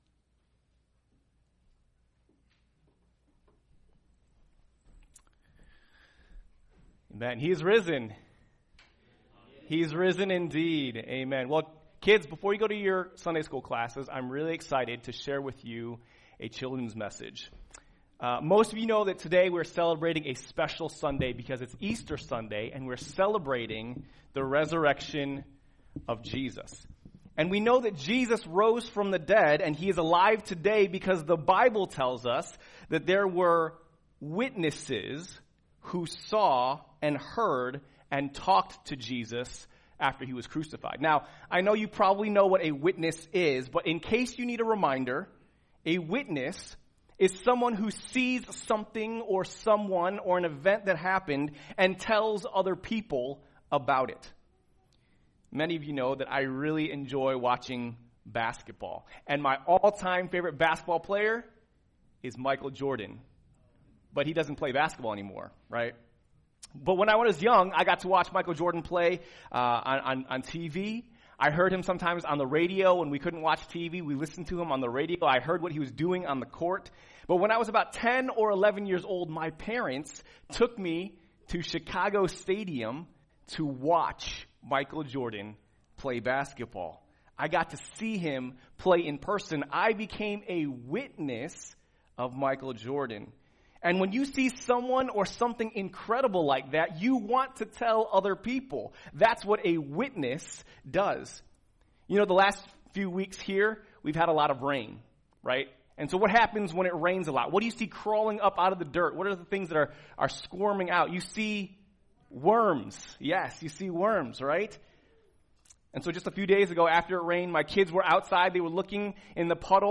[2022 Easter Children's Message] Risen! 祂復活了!